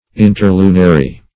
Search Result for " interlunary" : The Collaborative International Dictionary of English v.0.48: Interlunar \In`ter*lu"nar\, Interlunary \In`ter*lu"na*ry\, a. [Pref. inter- + lunar: cf. L. interlunis.]